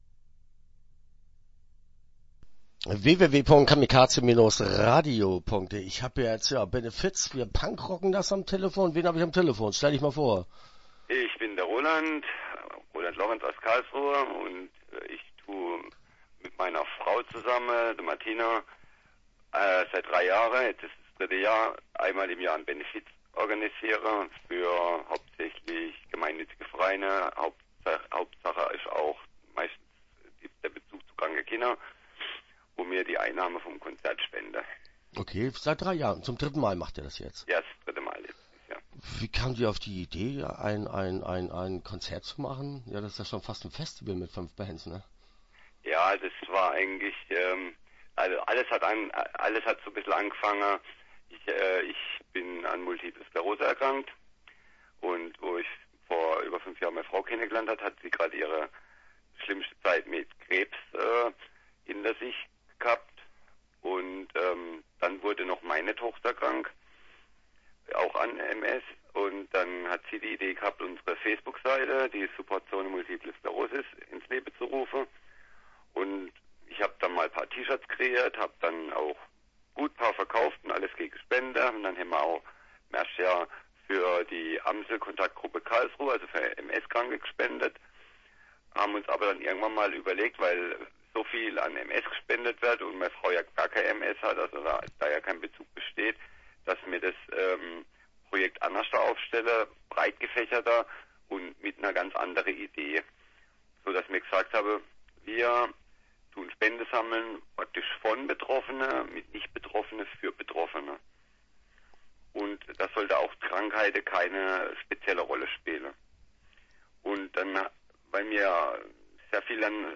Start » Interviews » Supportzone Multiple Sclerosis